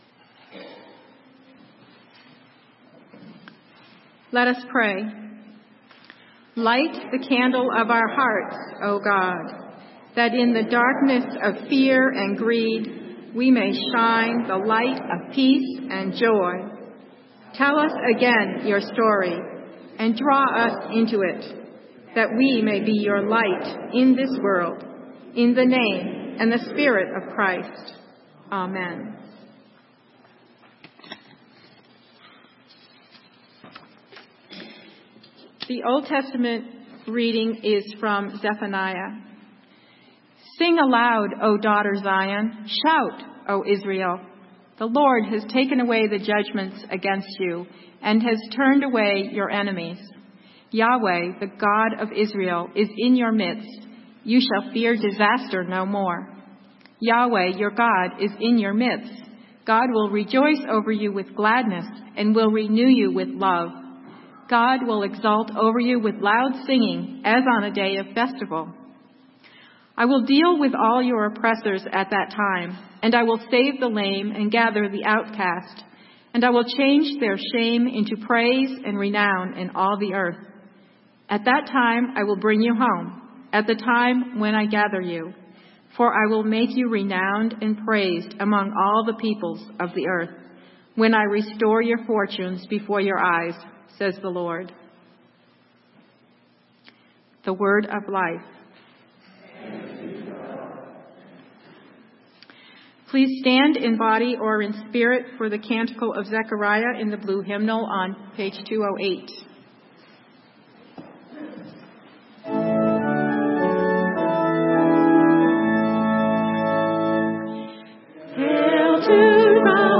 Sermon: The Real Story - St. Matthew's UMC